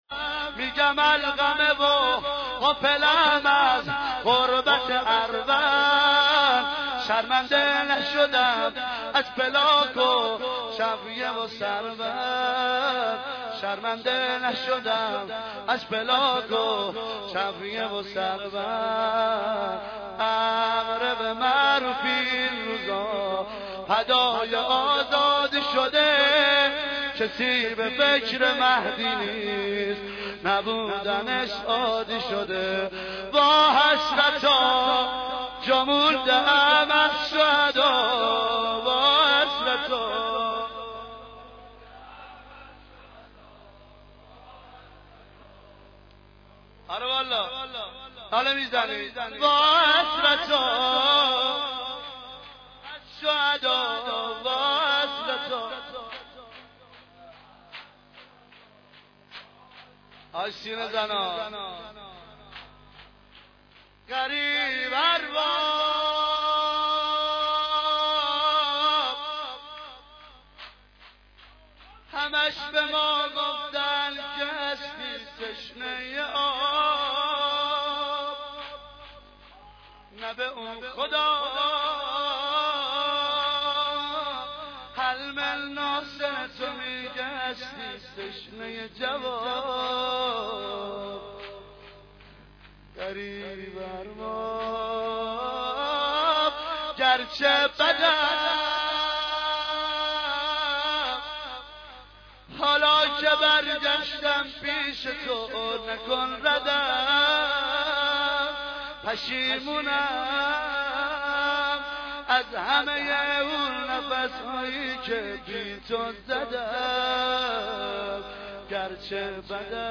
maddahi-197.mp3